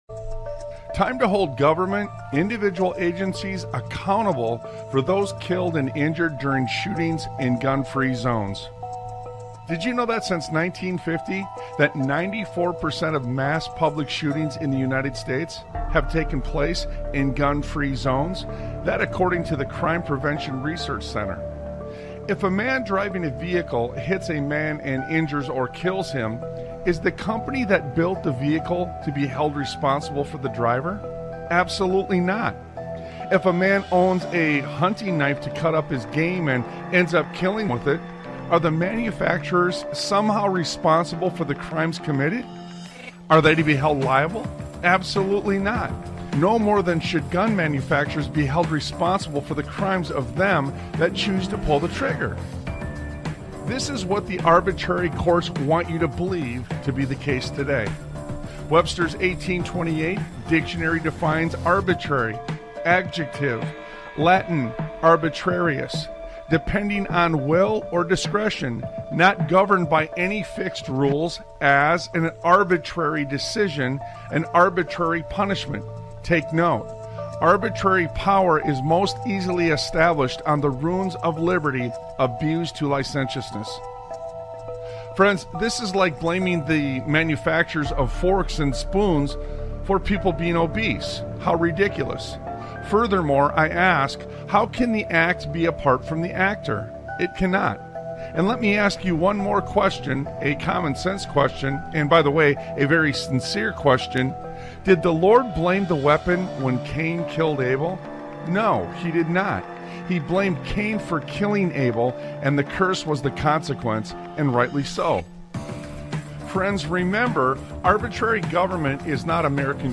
Talk Show Episode, Audio Podcast, Sons of Liberty Radio and Gun Owners Of America To Pam Bondi: No Way! on , show guests , about Gun Owners Of America To Pam Bondi: No Way, categorized as Education,History,Military,News,Politics & Government,Religion,Christianity,Society and Culture,Theory & Conspiracy